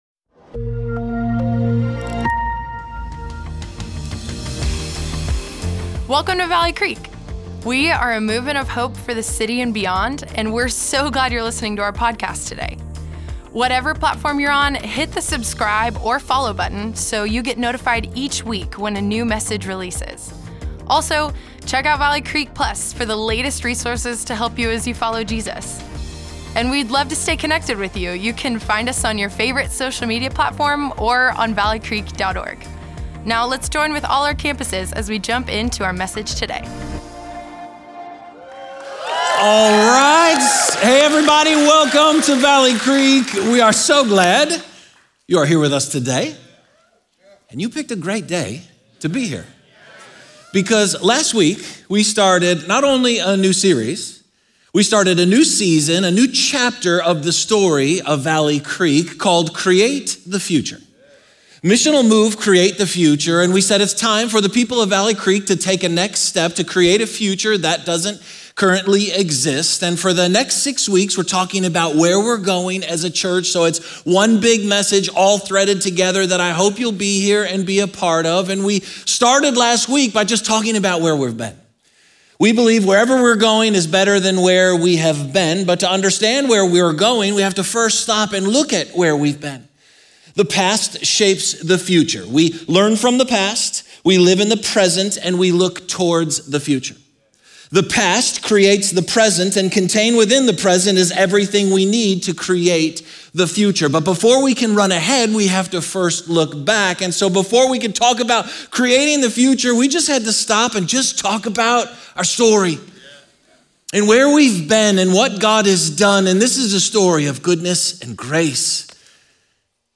Valley Creek Church Weekend Messages It's Time to Move Feb 02 2025 | 00:56:16 1x 00:00 / 00:56:16 Subscribe Share Apple Podcasts Spotify Amazon Music Overcast RSS Feed Share Link Embed